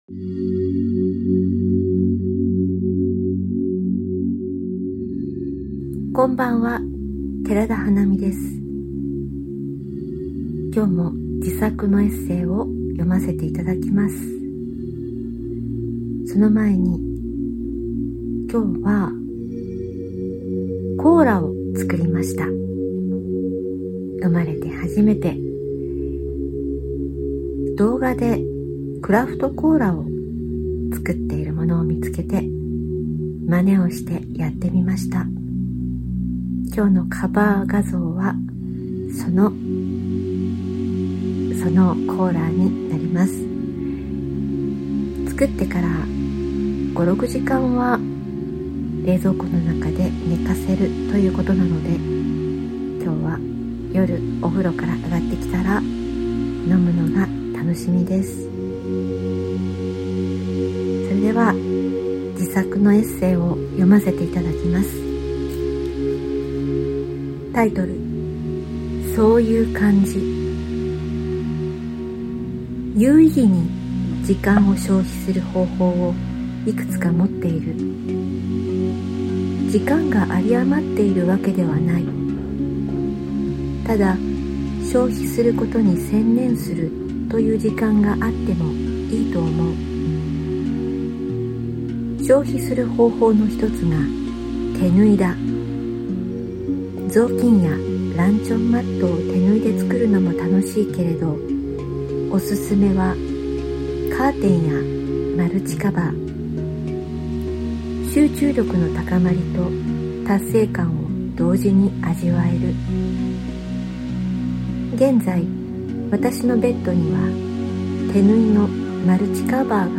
聴くエッセイ『そういう感じ』 - 聴くエッセイ
Audio Channels: 2 (stereo)